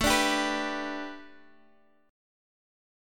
Listen to A7sus2sus4 strummed